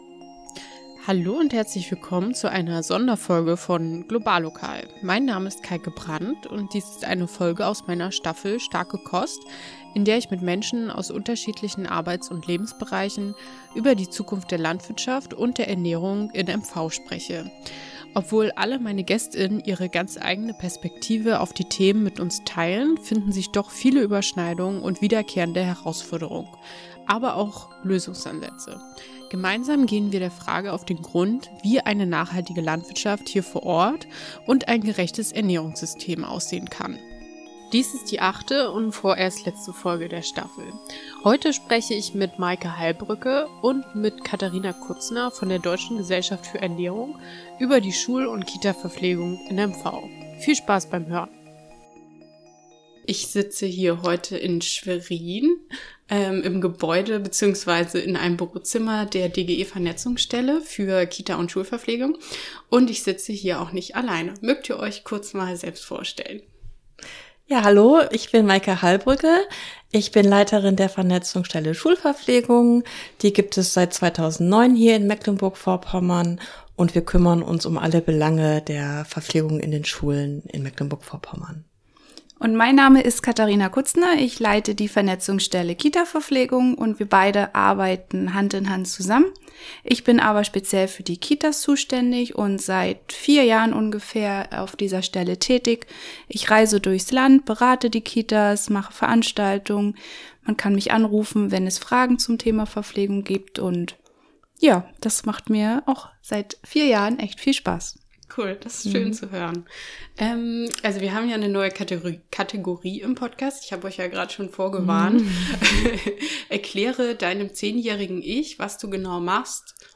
Das Gespräch wurde im Januar 2024 aufgenommen.